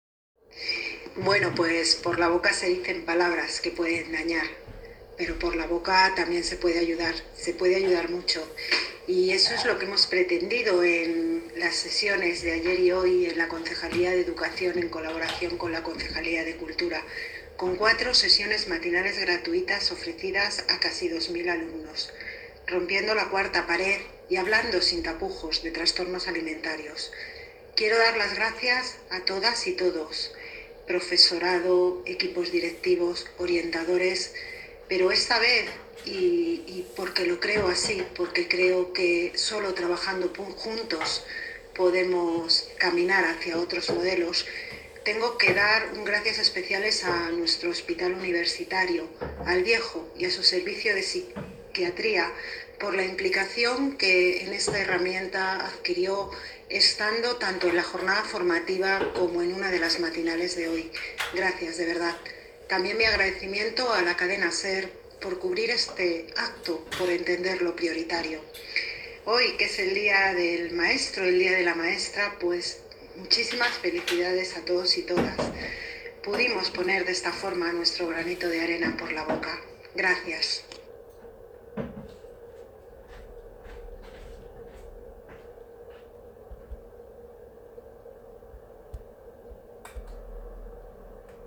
Audio - María Isabel Cruceta (Concejala de Educación) Sobre Por la Boca